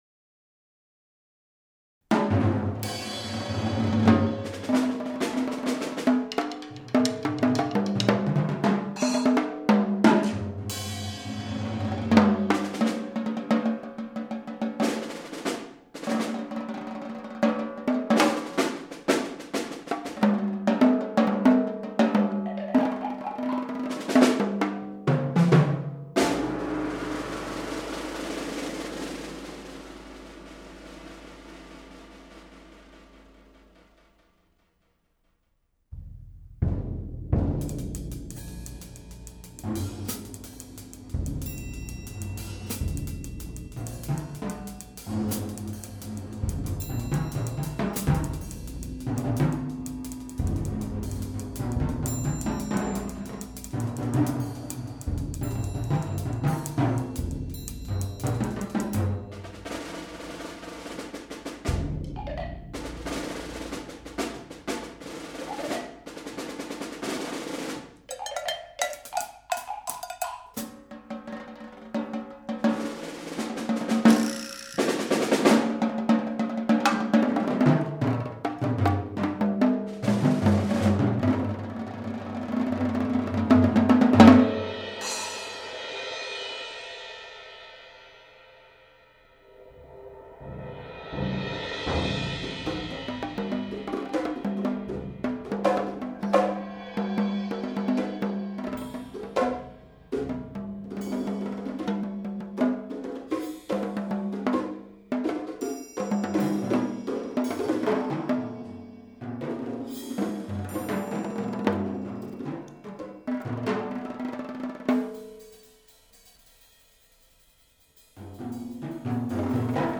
Genre: Percussion Quartet
# of Players: 4